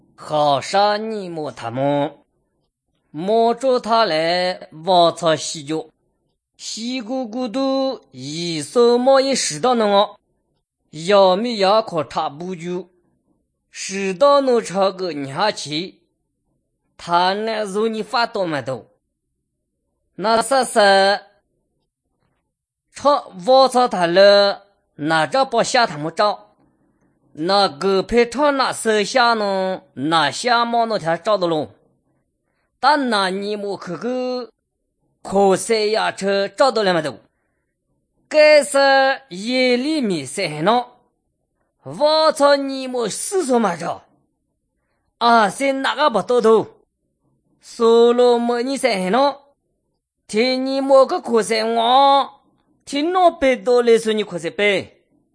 29 August 2016 at 12:17 am I hear tones and phonemes similar to Mandarin, but it’s not.
But having seen that it’s a Tibeto-Burman language, I’m going to go for Bai, with the tense and harsh voice qualities I’m hearing in various syllables.